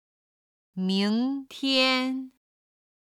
ただ軽音部分の音源がないので、元々の単語の四声にしています。
音源には本来の四声の数字を表記(軽声は5と表記)、音と目で音源の四声が分かるようにしています。